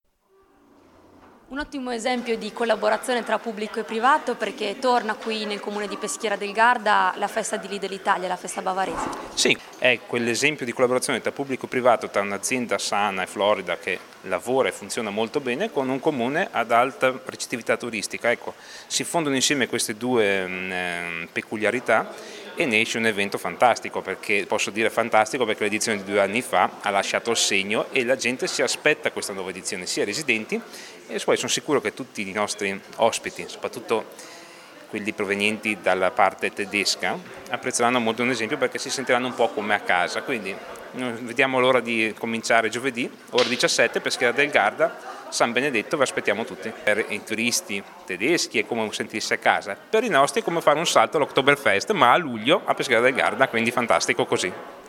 Di seguito le interviste ai protagonisti:
Filippo Gavazzoni, assessore al Turismo e vicesindaco Peschiera del Garda